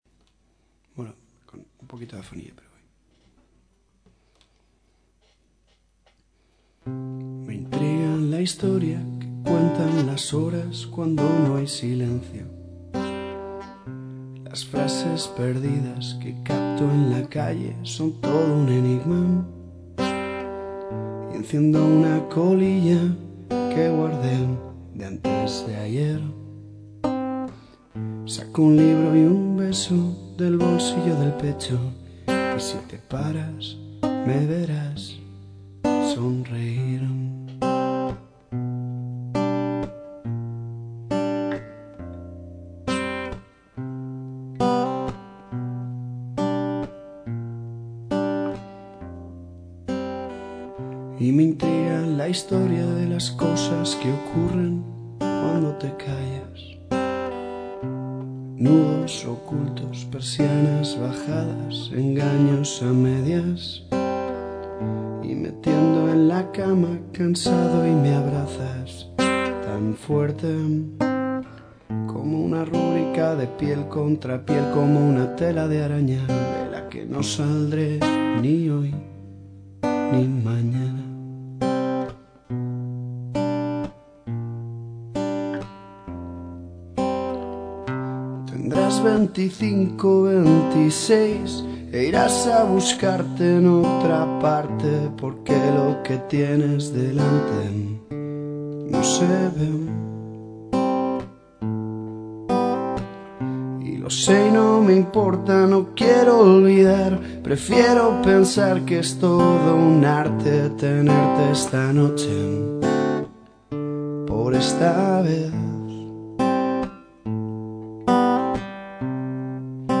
Con la afonía terrible que arrastro desde Festimad, ahí va una canción triste